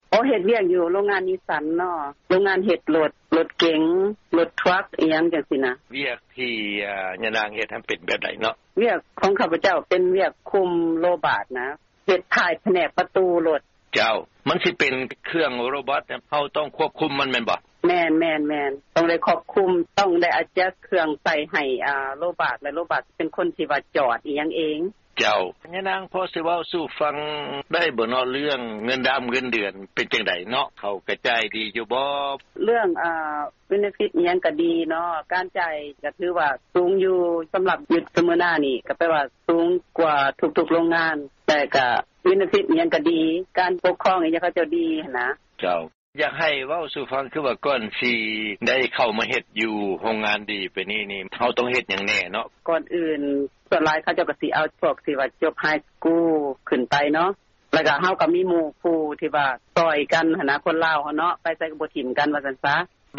ທີ່ທ່ານຫາກໍໄດ້ຮັບຟັງຜ່ານໄປນັ້ນ ແມ່ນການໂອ້ລົມກັບຄອບຄົວຄົນລາວ ໃນເຂດເມືອງສເມີນາ ລັດເທັນເນັສຊີ.